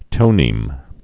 (tōnēm)